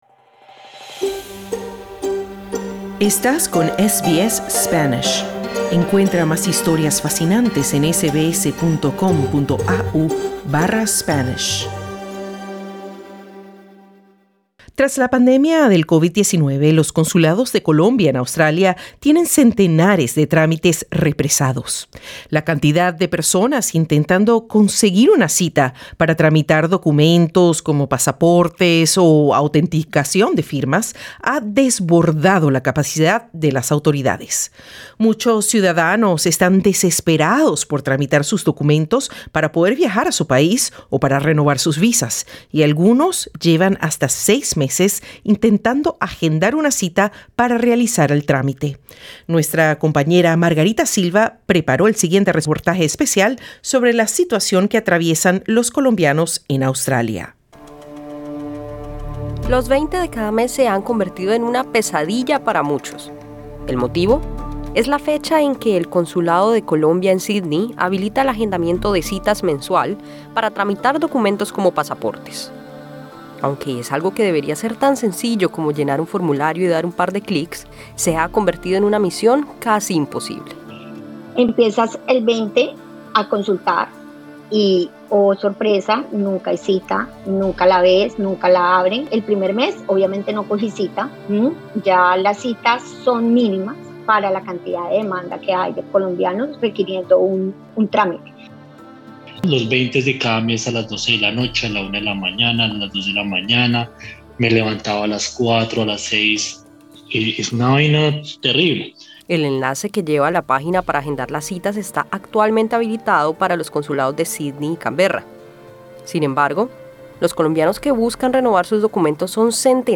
Algunos llevan hasta seis meses intentando agendar una cita para realizar el trámite, mientras las autoridades no se dan abasto. SBS Spanish conversó con afectados y buscó respuestas de la Embajada de Colombia en Australia.